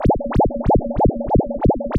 Sine Wave Modulated Phaser.wav